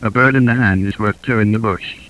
Speech synthesis demo
Everything including the fundamental frequency (parameters 1-9)
Bird(full).aiff